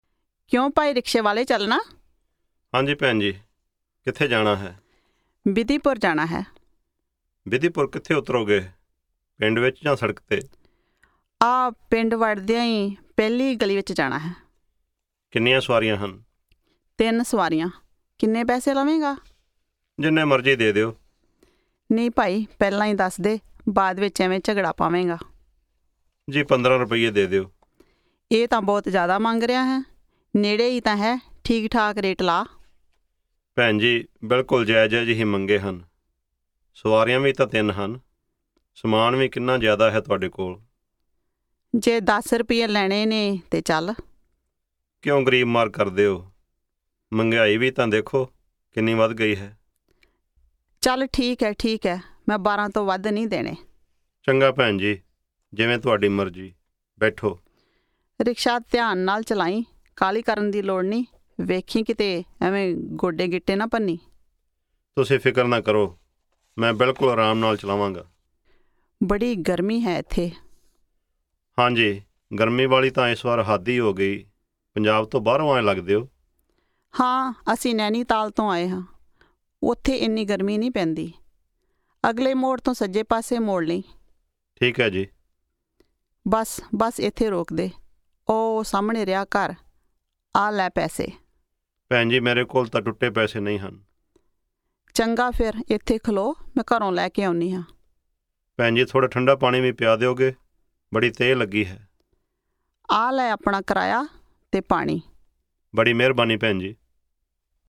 Punjabi Conversation 8 Listen
A passenger talks to the rickshaw driver.